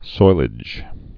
(soilĭj)